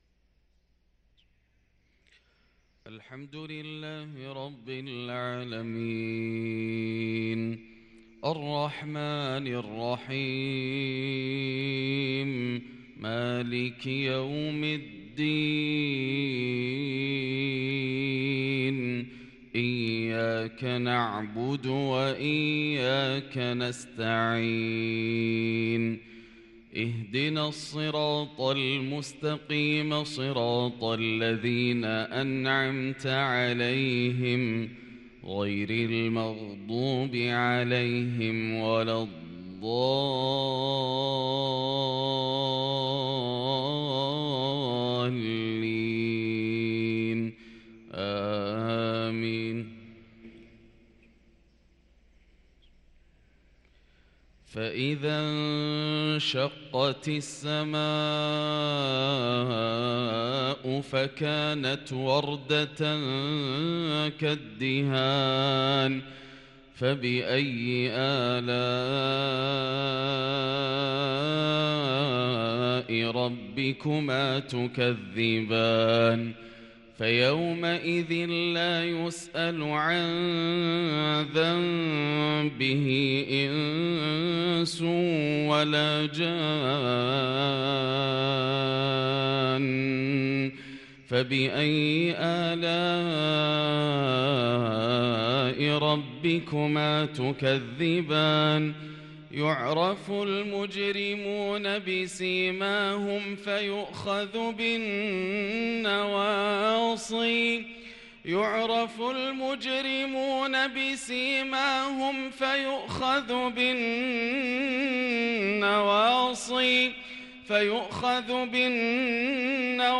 صلاة الفجر للقارئ ياسر الدوسري 29 ربيع الآخر 1444 هـ